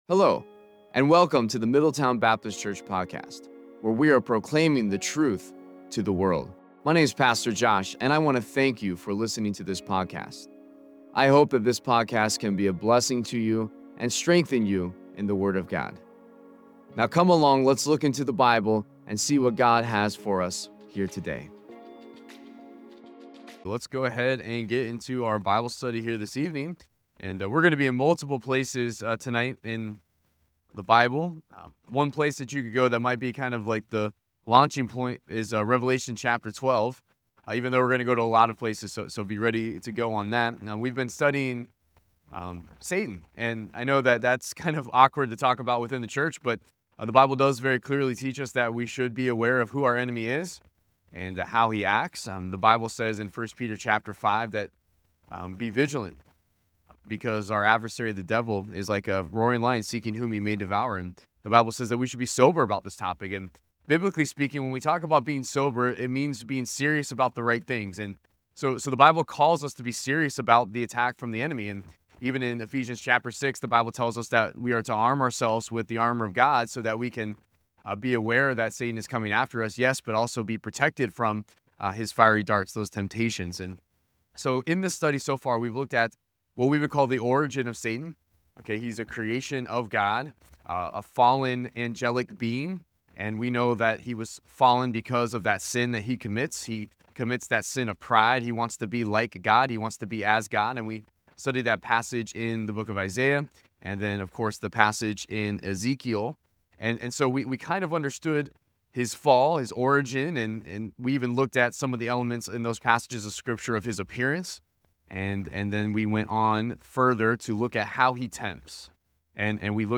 The sermon delves into Satan's origins as a fallen angel, elucidating the transformation from a being of light to the embodiment of darkness and rebellion against God.
Worship-Service-April-9-2025.mp3